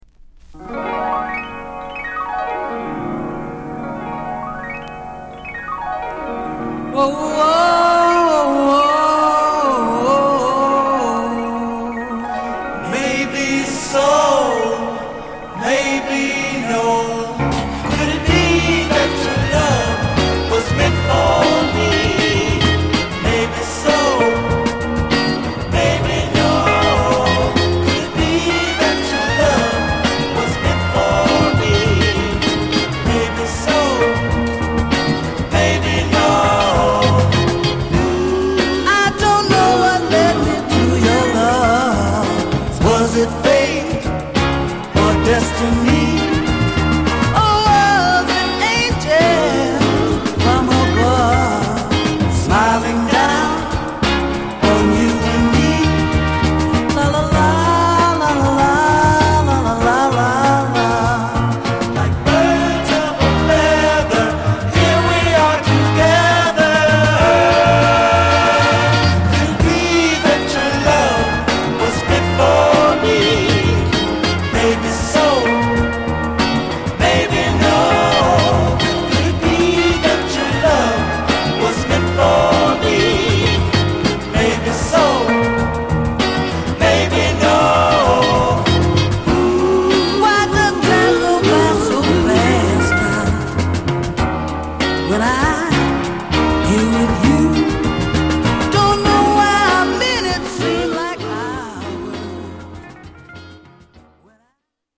薄いスレは程々に見られますが再生は良好です。